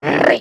Minecraft Version Minecraft Version snapshot Latest Release | Latest Snapshot snapshot / assets / minecraft / sounds / entity / shulker / ambient2.ogg Compare With Compare With Latest Release | Latest Snapshot